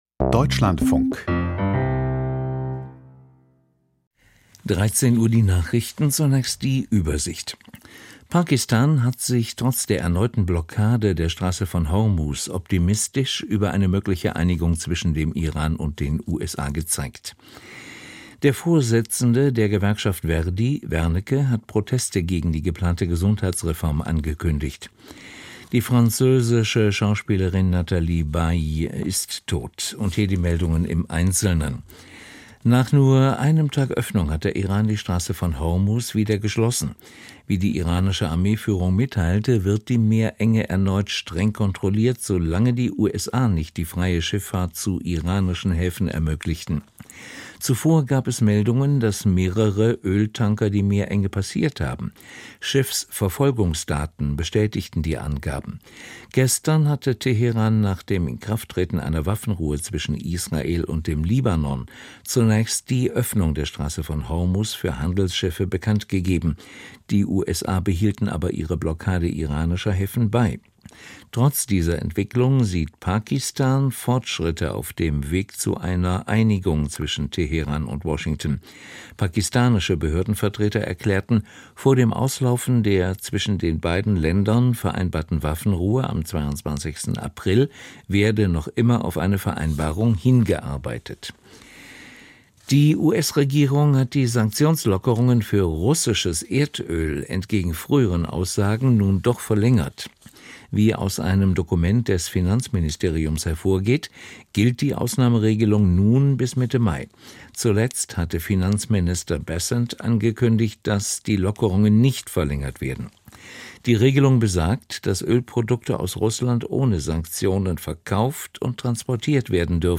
Die Nachrichten vom 18.04.2026, 13:00 Uhr
Aus der Deutschlandfunk-Nachrichtenredaktion.